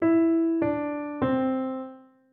Rozpoznawanie trybu melodii (smutna,wesoła)